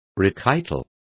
Complete with pronunciation of the translation of requital.